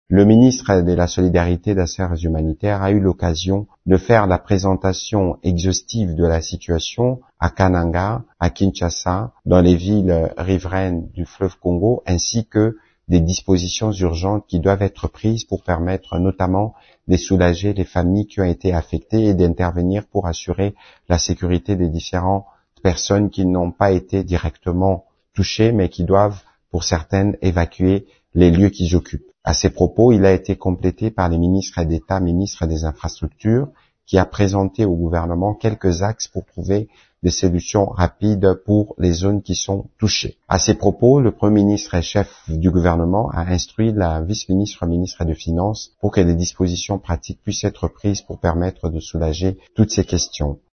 Ecoutez cet extrait du compte-rendu de cette réunion fait par le ministre Patrick Muyaya, porte-parole du Gouvernement :